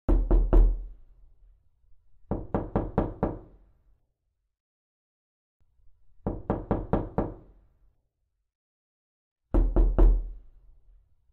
batida-de-porta-troll_i8wVFmn.mp3